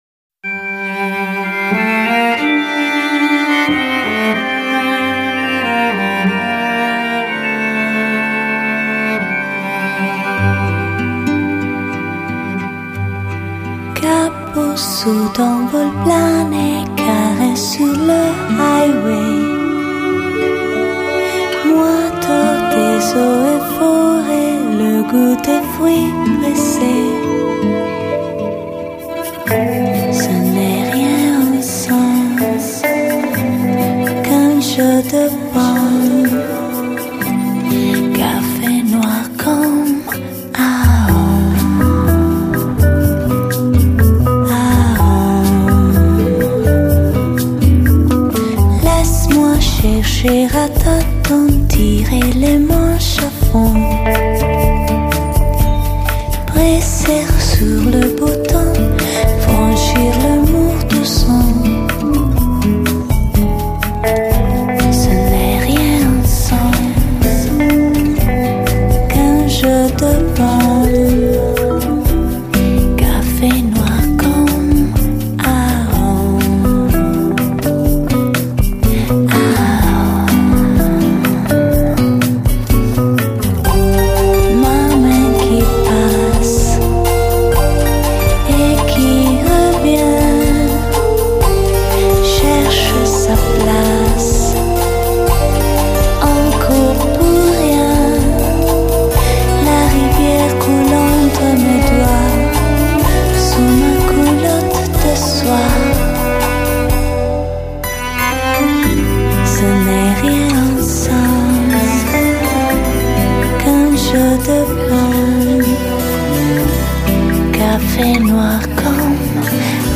Genre: Lo-Fi, Bossa Nova, Lounge